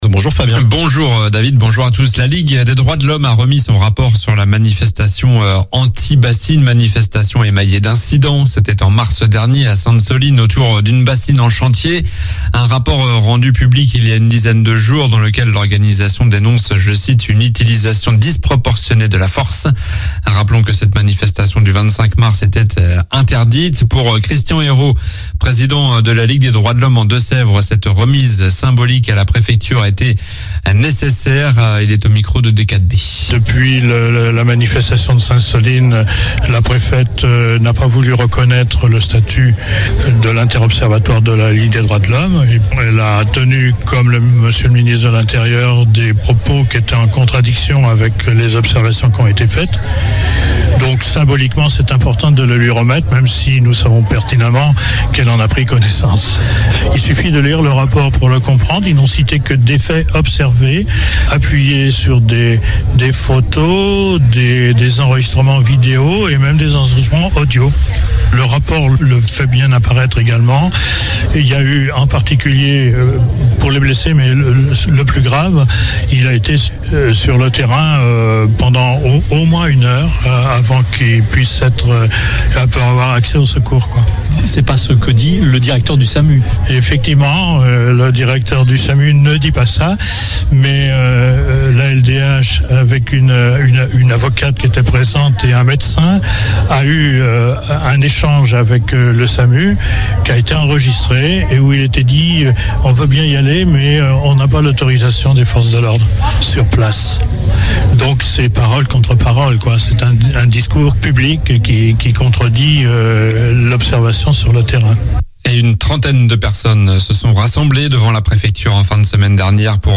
Journal du lundi 24 juillet (matin)